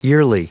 Prononciation du mot yearly en anglais (fichier audio)
Prononciation du mot : yearly